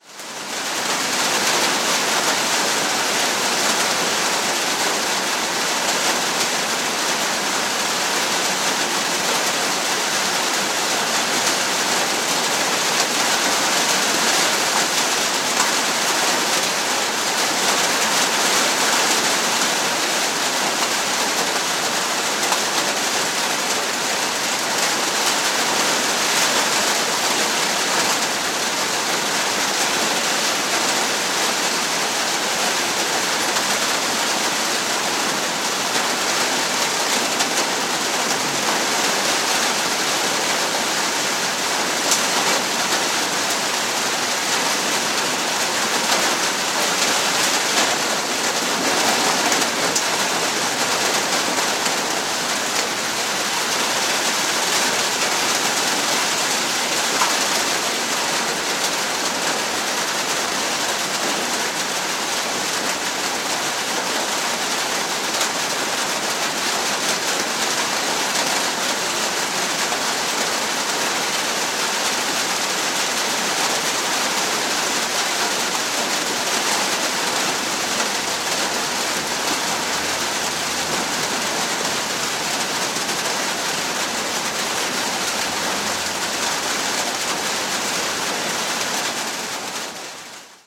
Звуки града
Градовый шторм